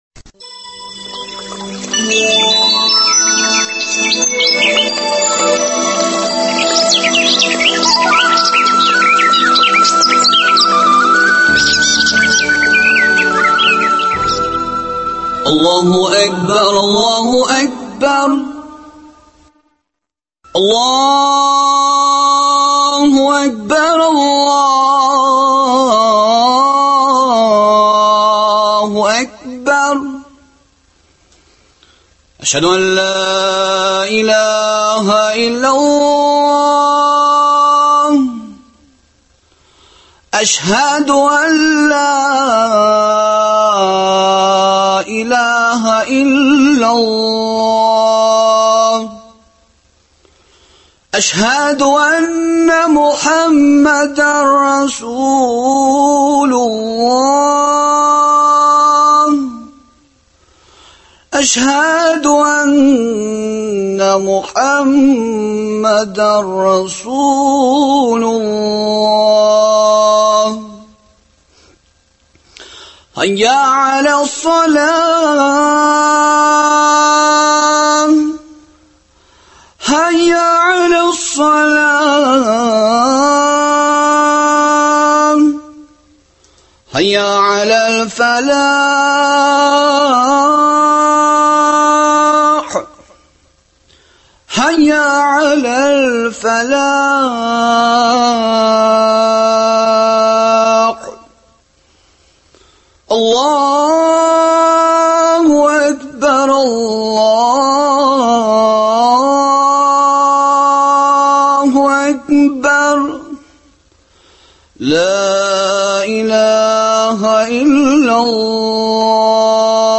изгелекнең кадере турында әңгәмә тыңларбыз.